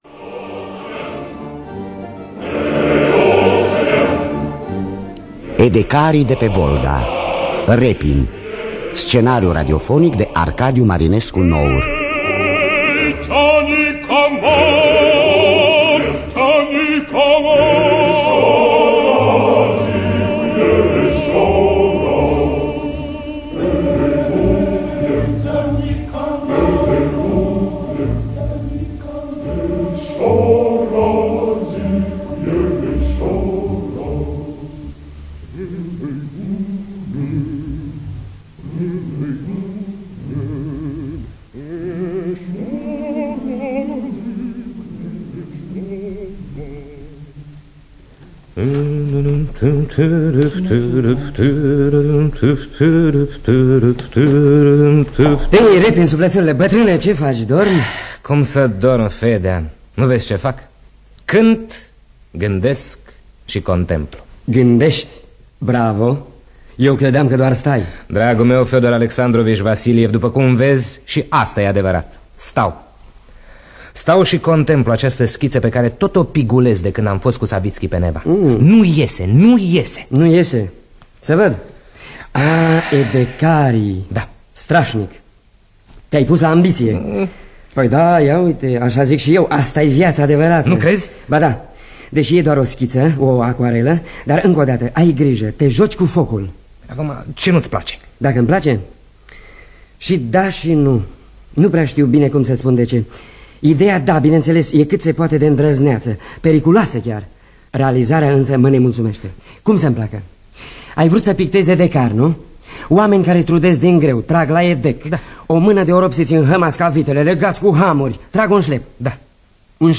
Biografii, Memorii: Ilya Repin – Edecarii De Pe Volga (1982) – Teatru Radiofonic Online